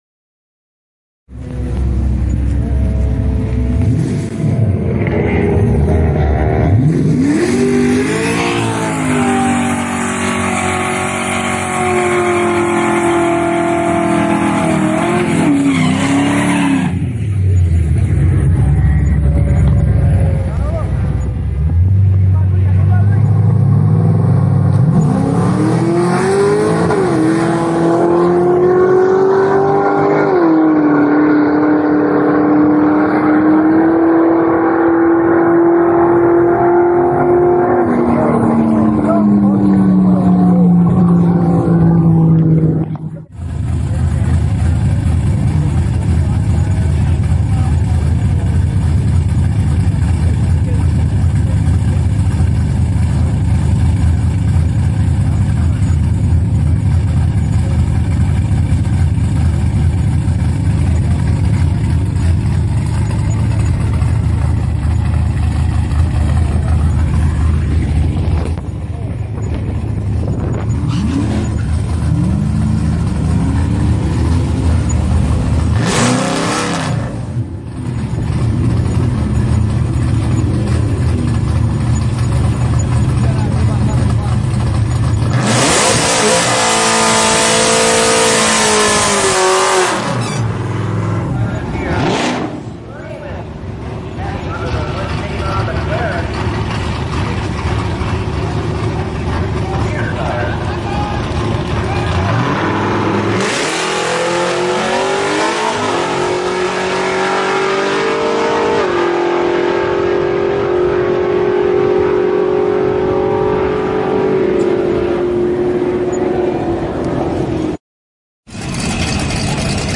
CAR ENGINE SOUNDS – USE OURS OR ONE OF YOURS…
muscle-car-sounds-49337.mp3